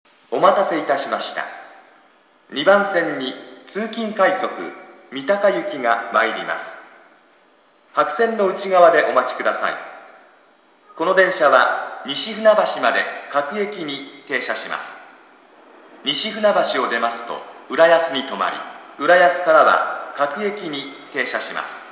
駅放送